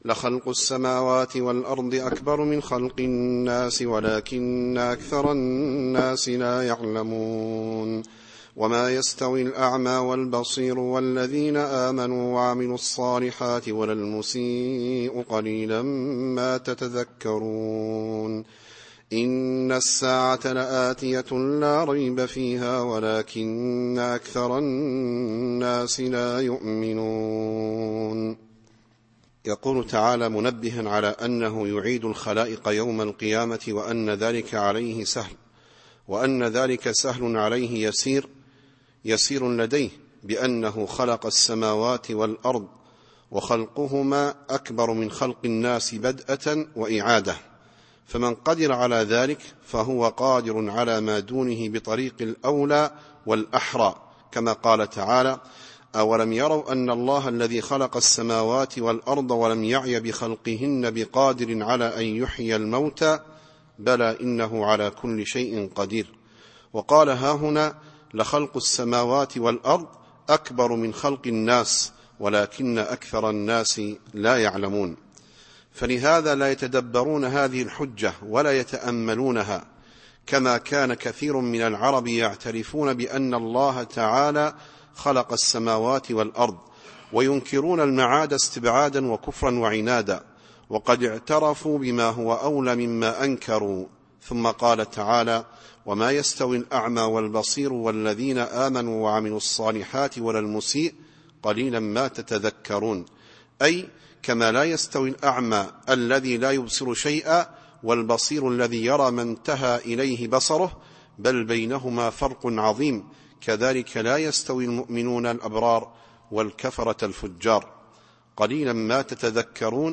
التفسير الصوتي [غافر / 57]